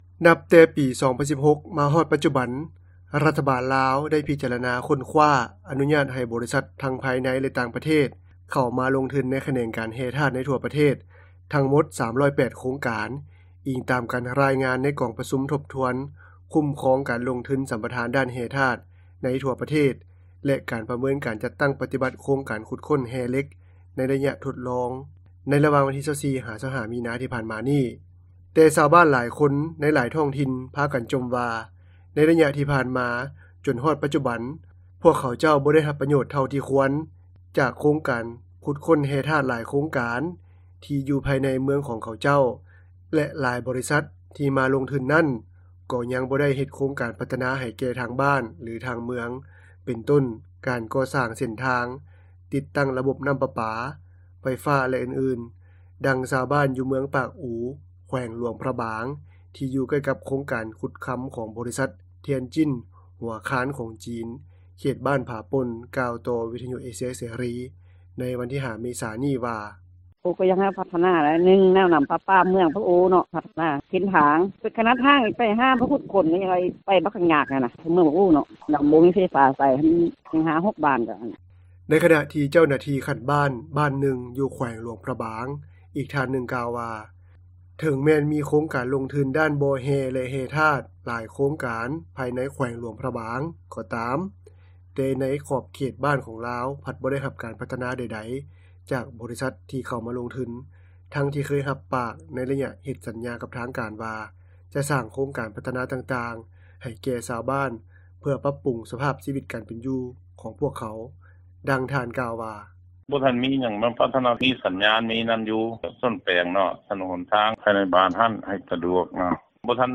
ດັ່ງຍານາງ ກ່າວວ່າ:
ດັ່ງຊາວບ້ານ ໃນພື້ນທີ່ດັ່ງກ່າວ ກ່າວວ່າ: